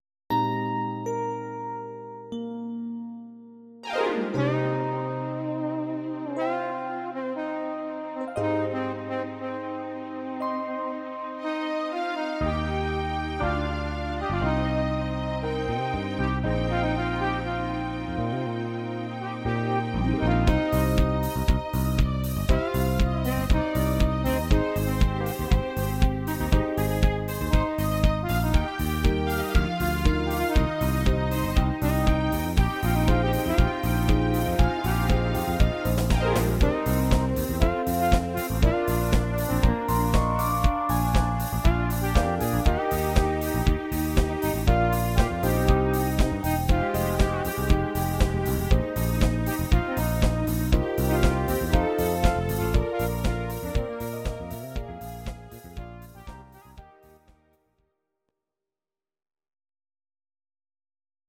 Audio Recordings based on Midi-files
Pop, Disco, 1970s